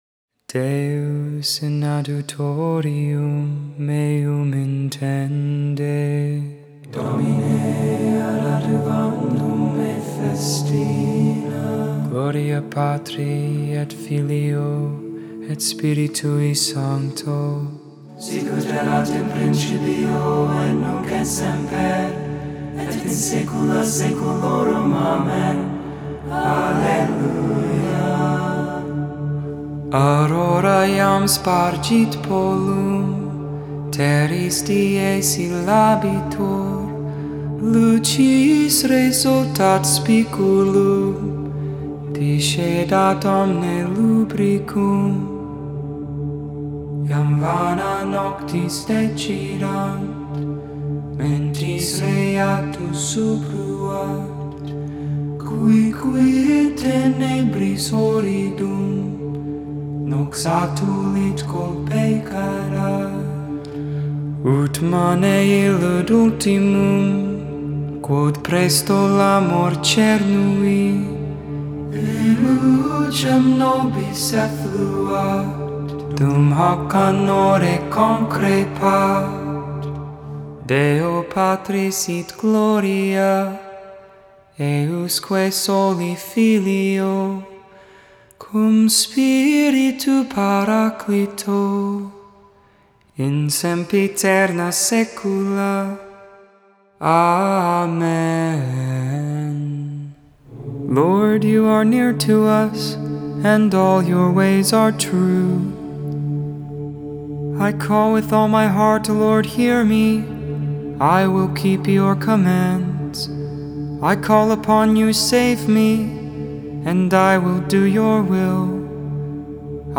1.30.21 Lauds (Sat Morning Prayer)